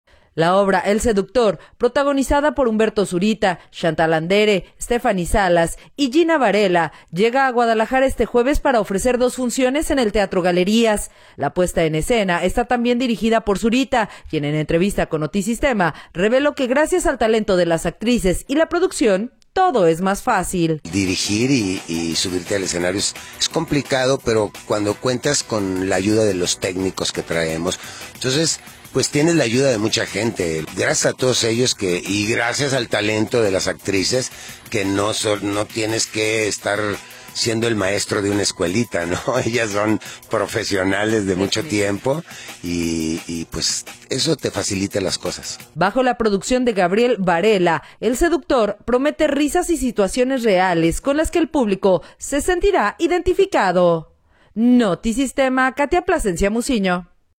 audio La obra “El Seductor” protagonizada por Humberto Zurita, Chantal Andere, Stephanie Salas y Gina Varela llega a Guadalajara este jueves para ofrecer dos funciones en el Teatro Galerías. La puesta en escena está también dirigida por Zurita, quien en entrevista con Notisistema reveló que gracias al talento de las actrices y la producción todo es más fácil.